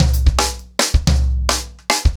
TimeToRun-110BPM.5.wav